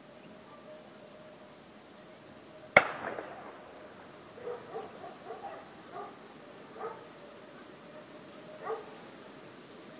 火器 " 单发步枪射击 1
描述：现场录制步枪＃3。
Tag: 枪械 射击 步枪 射击 武器 FX 镜头